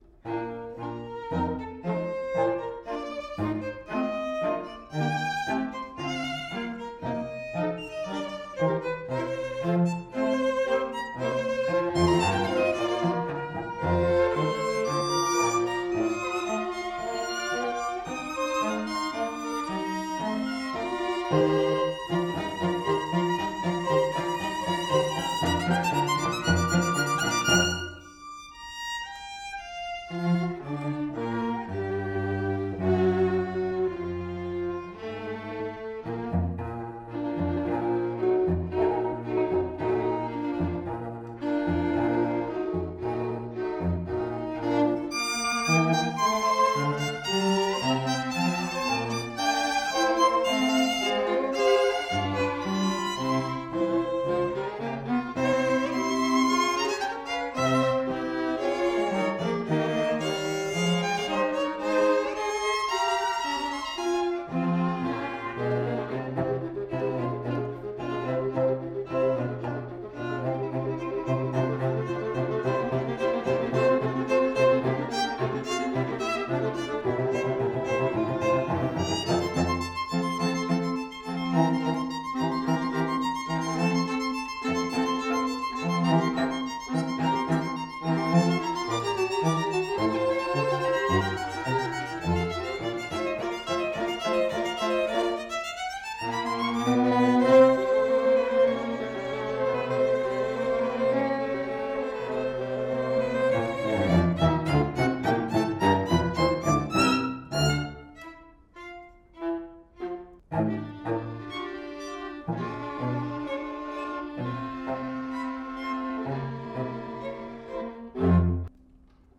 The lively finale, Allegro, is a kind of singing march.